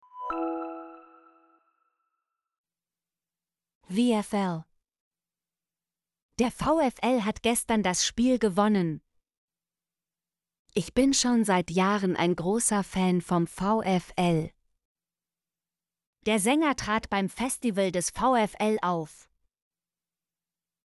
vfl - Example Sentences & Pronunciation, German Frequency List